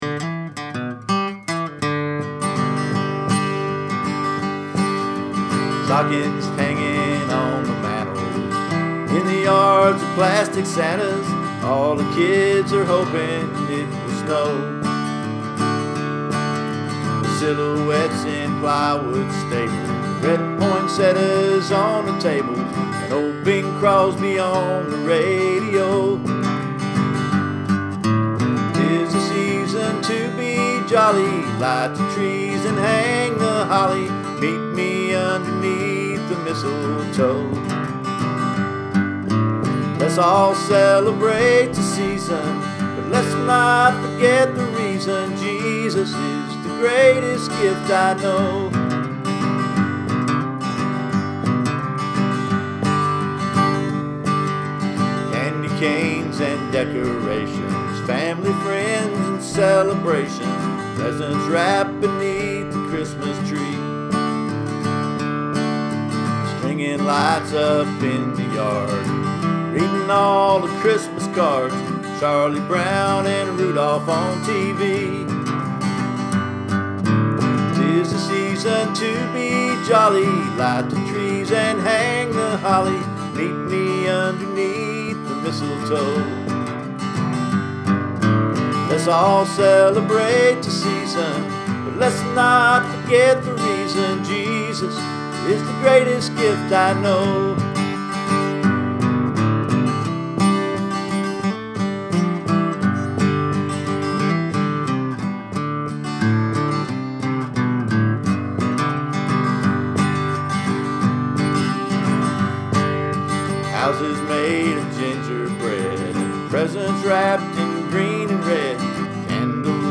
Here are 3 Christmas songs I have written over the years. These recordings are admittedly lo-fidelity. I made them in Garage Band sitting in my living room with just my guitar and a microphone connected to my laptop.